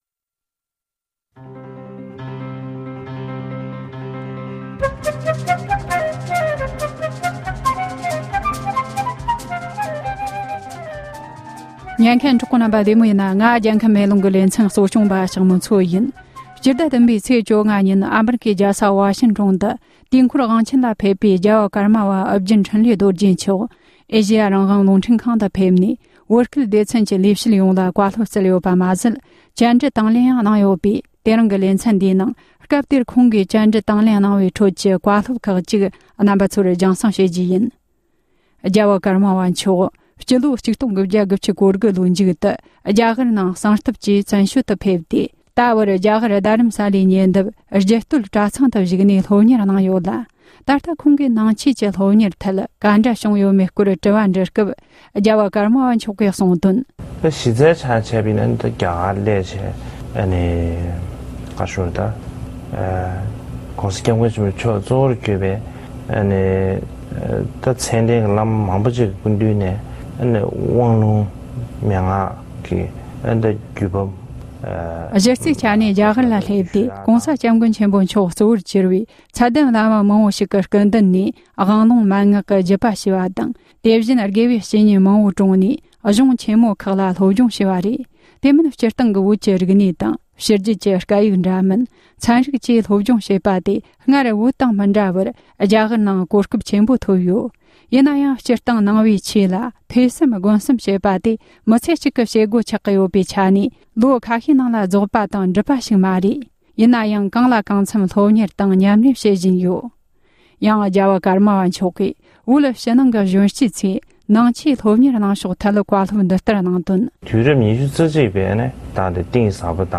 ཀརྨ་པ་མཆོག་ལ་བཅར་འདྲི།